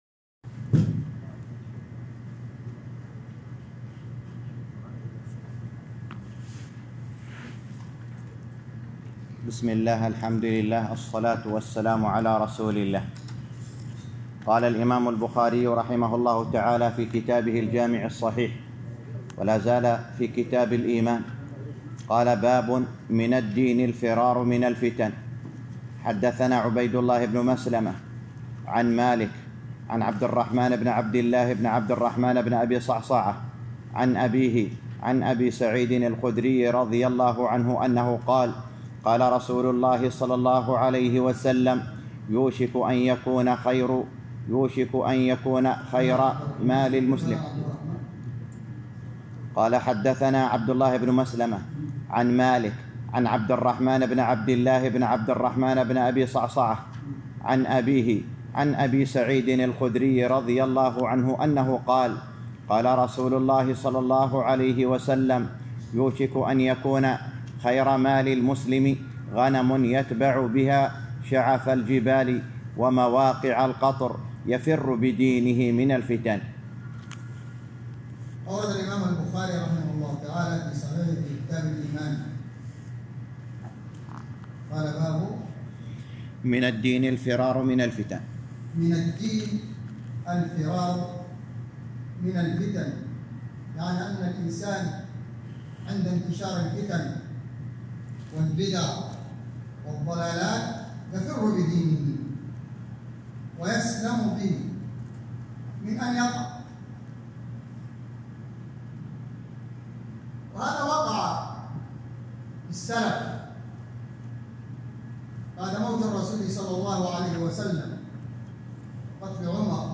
الدرس السابع